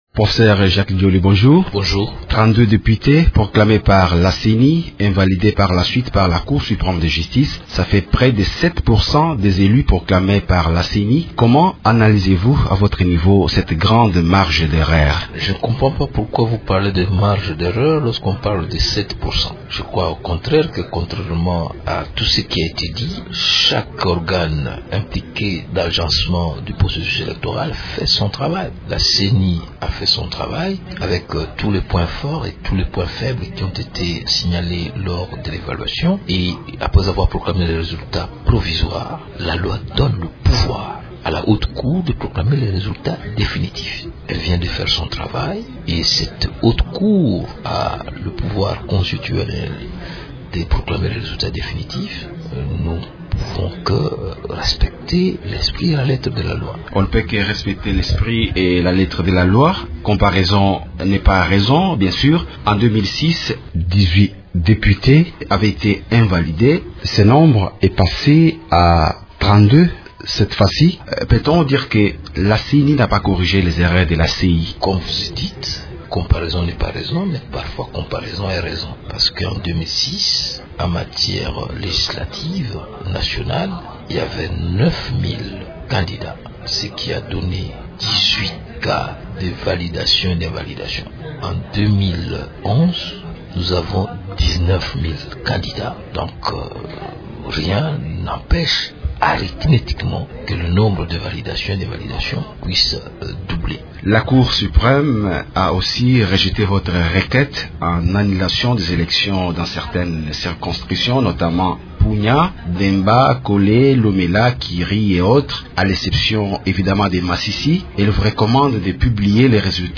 Le vice-président de la ceni, Jacques Djoli, explique que chaque institution a fait son travail et que tout le monde ne peut que respecter l’esprit et la lettre de la loi.